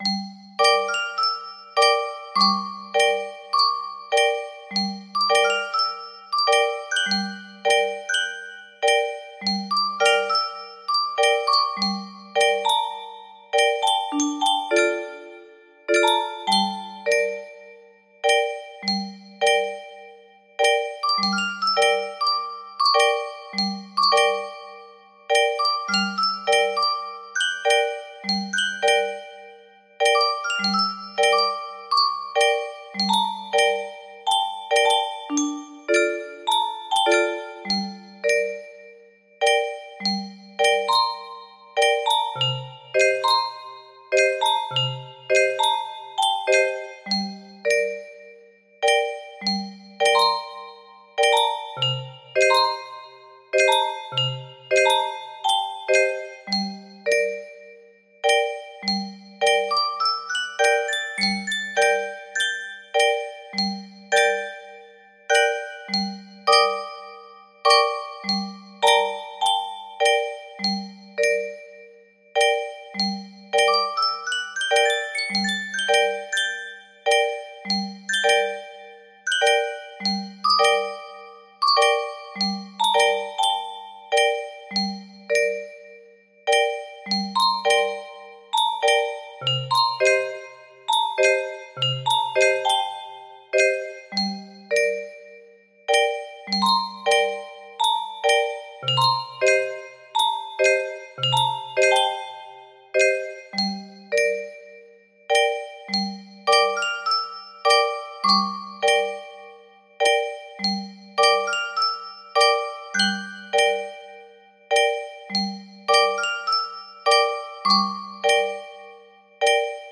Gnossienne No. 1 music box melody
Full range 60